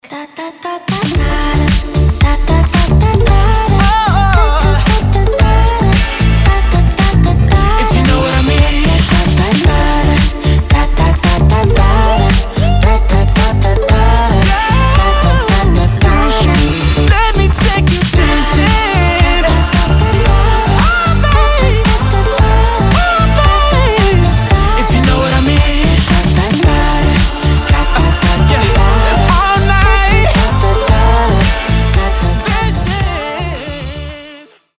• Качество: 320, Stereo
заводные